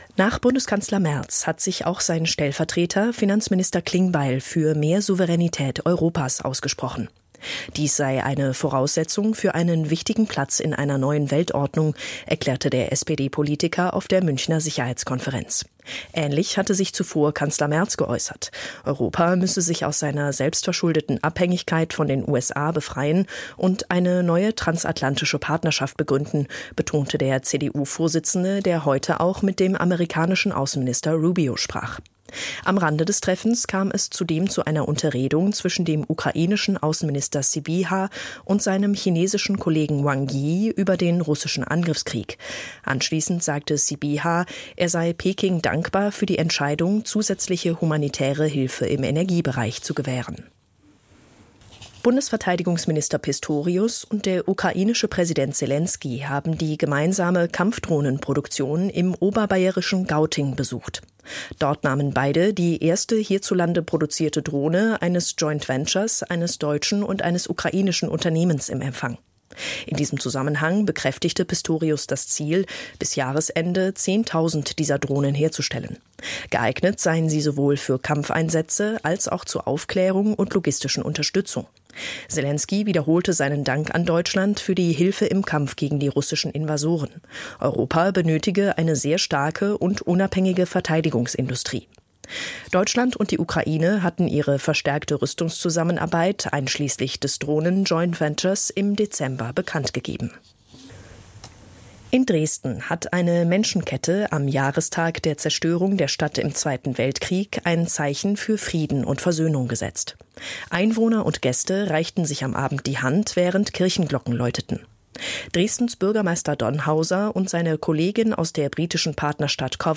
SÜDTIROL AKTUELL NACHRICHTEN ZUM NACHHÖREN HITRADIOSUEDTIROL ONLINE
NACHRICHTEN-10.mp3